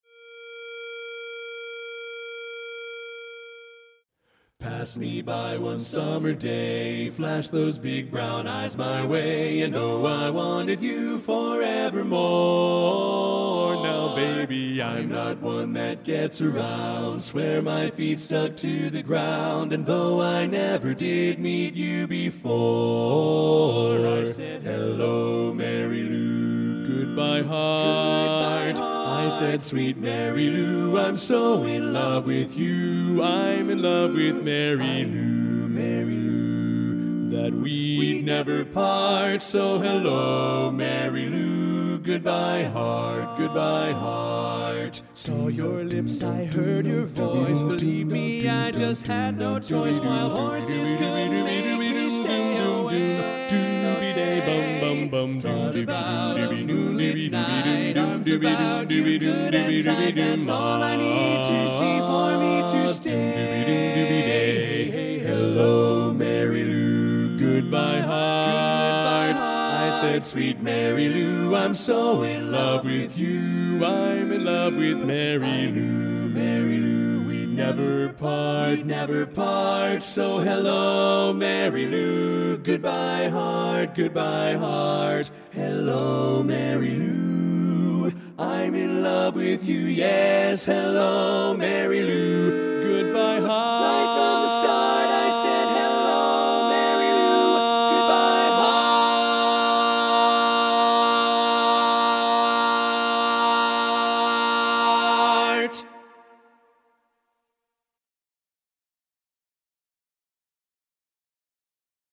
Kanawha Kordsmen (chorus)
Up-tempo
B♭ Major
Bass